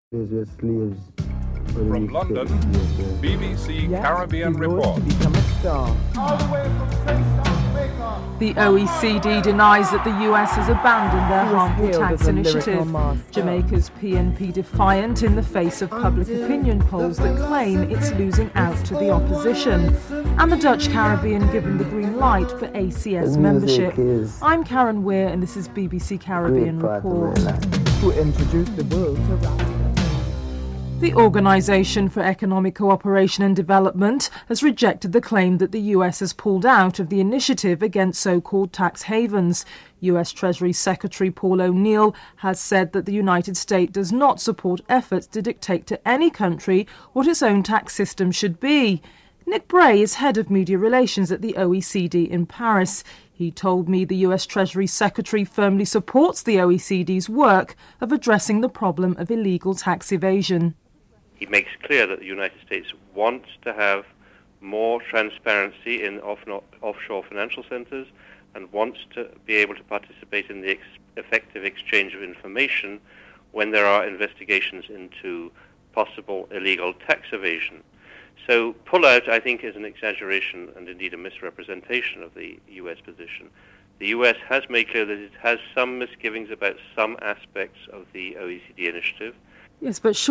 1. Headlines (00:00-00:30)
St. Lucia Commissioner of Police Brian Bernard and Attorney General Petras Compton are interviewed.